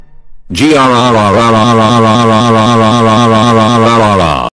GRRR sound effect (Roblox noobs OS) - Botón de Efecto Sonoro